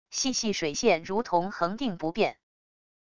细细水线如同恒定不变wav音频